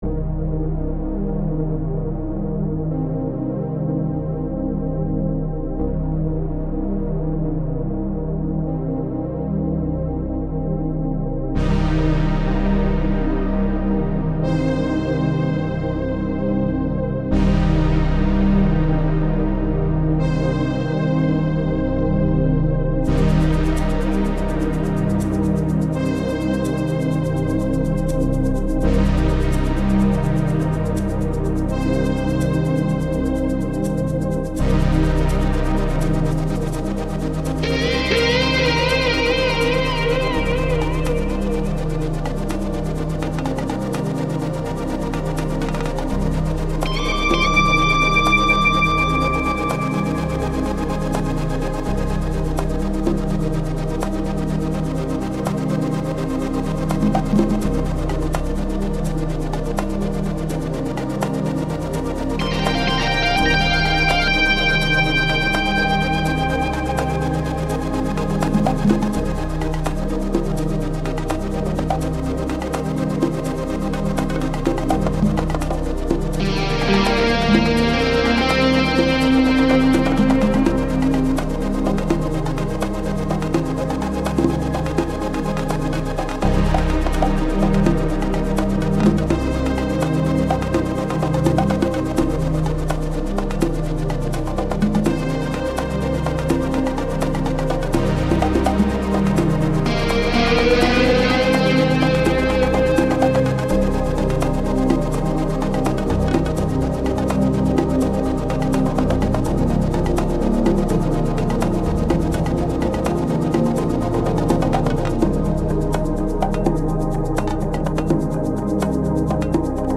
موزیک بی کلام